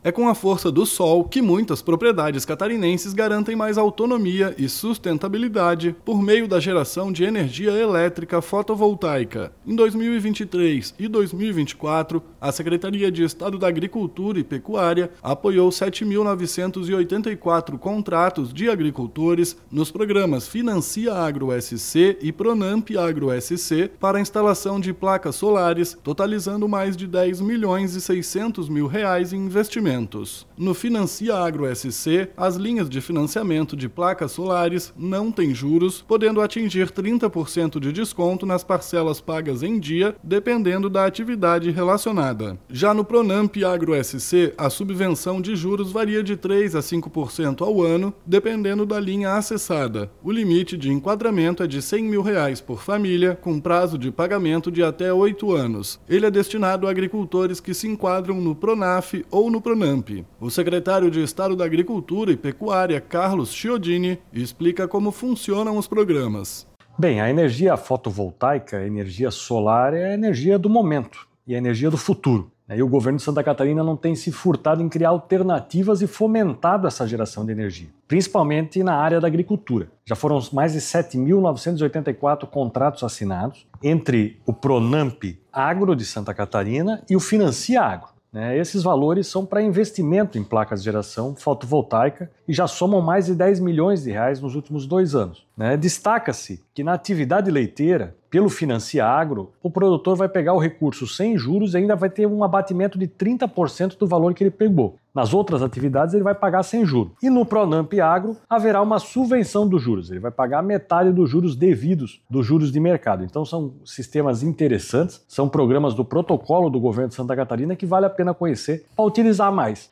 BOLETIM – Programas do Governo do Estado impulsionam sustentabilidade e economia no campo com energia solar
O secretário de Estado da Agricultura e Pecuária, Carlos Chiodini, explica como funcionam os programas: